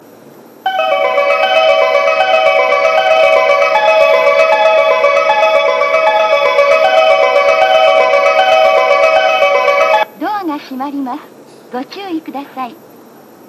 発車メロディー途中切りです。